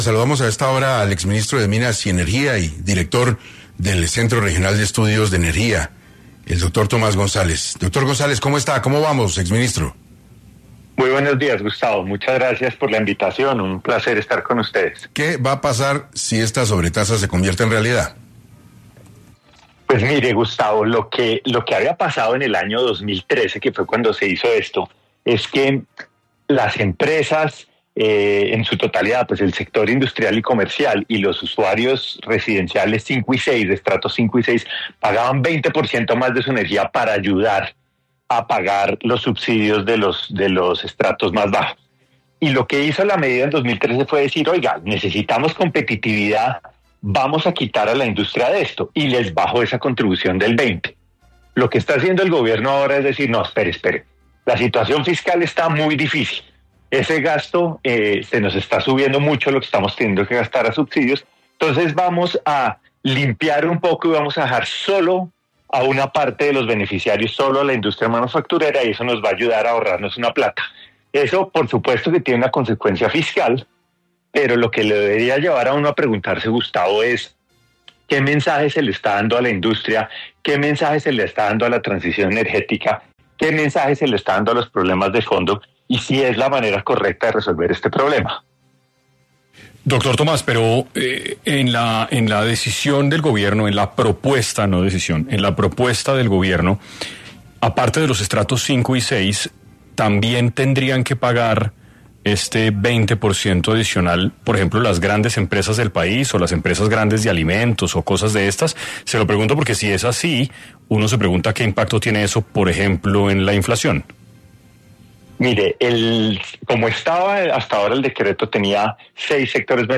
Tomás González, exministro de Minas y Energía, estuvo en 6AM para hablar de la publicación para comentarios de un borrador de decreto que propone un aumento del 20% en el costo de la energía eléctrica para distintas actividades económicas.